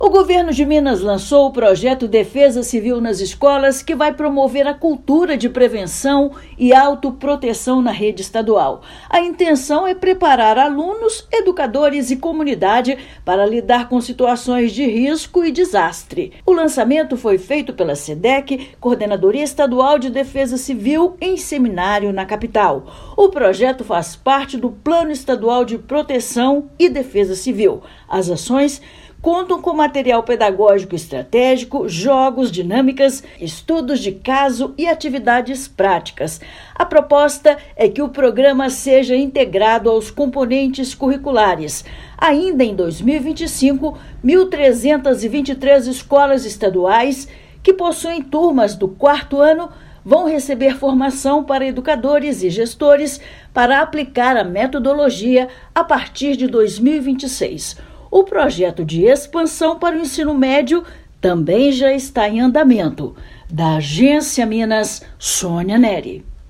Alunos do 4º ano do ensino fundamental de mais de mil escolas estaduais receberão material extracurricular que promove a cultura de prevenção e autoproteção. Ouça matéria de rádio.